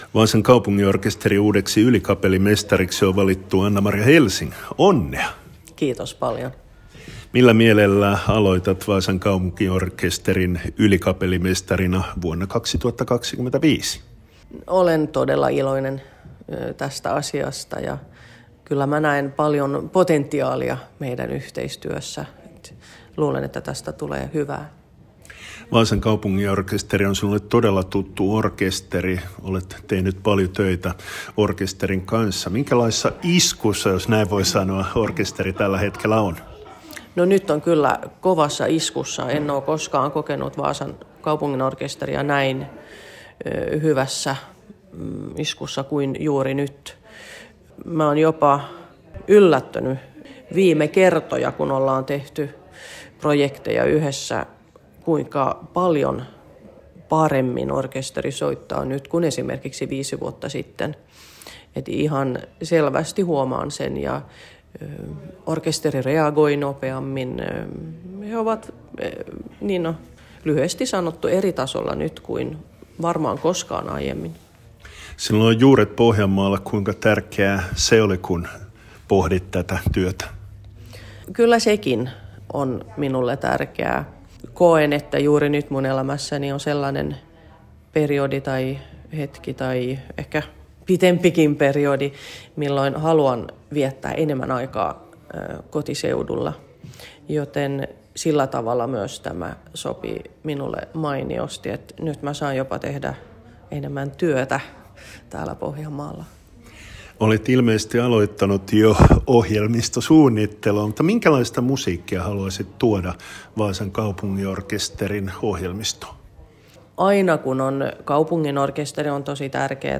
Anna-Maria Helsing intervjuas